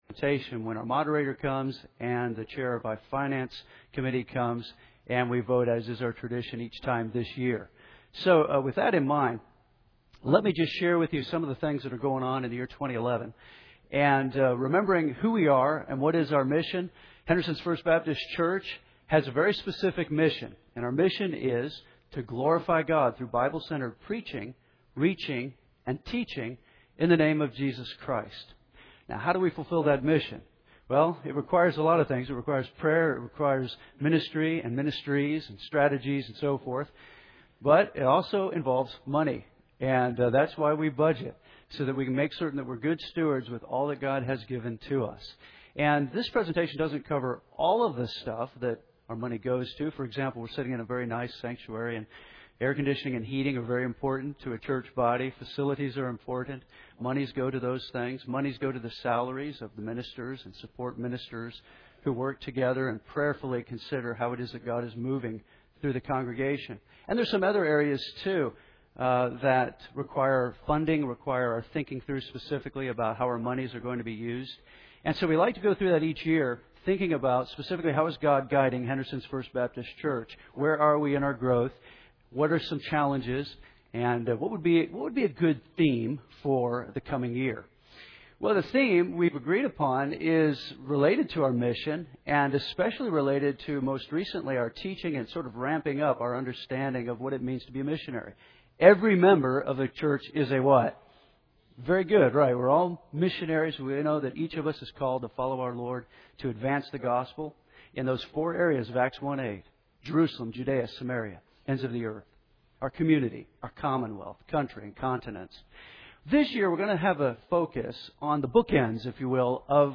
We are preaching our way through the Book of Luke, verse-by-verse, allowing the Bible to determine our topic each week.